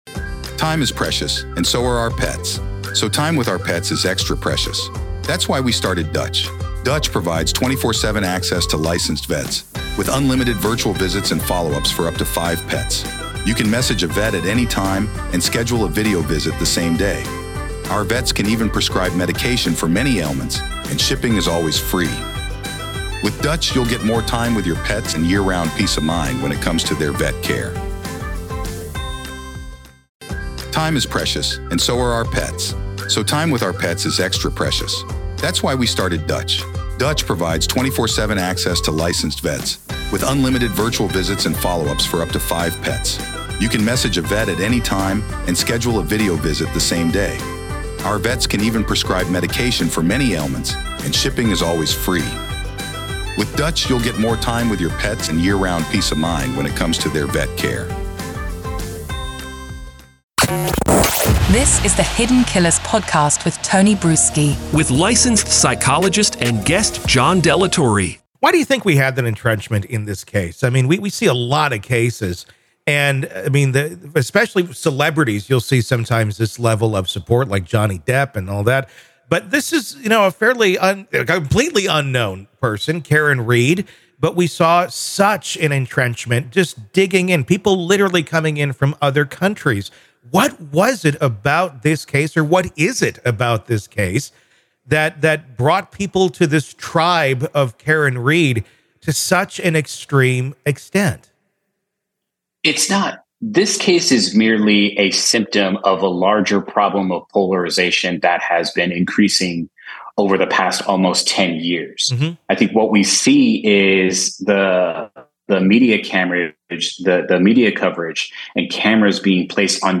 The conversation delves into the factors that have driven people to passionately align with one side, despite Karen Read being relatively unknown before the trial.